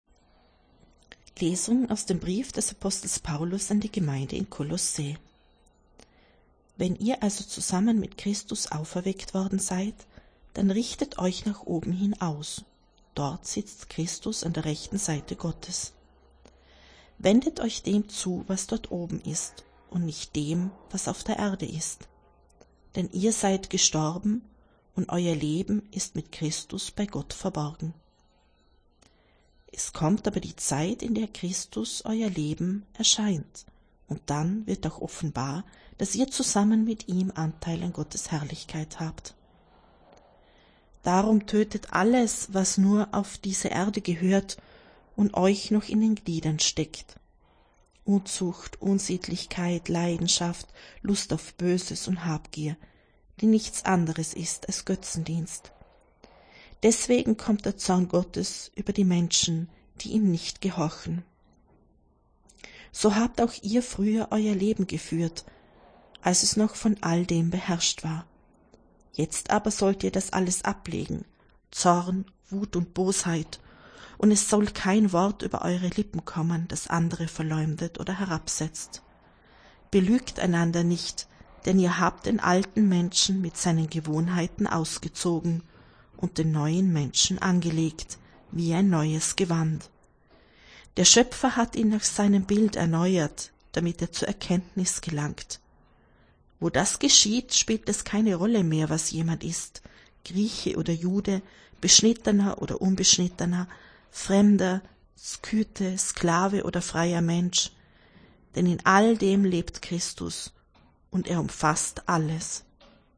Wenn Sie den Text der 2. Lesung aus dem Brief des Apostels Paulus an die Gemeinde in Kolóssä anhören möchten: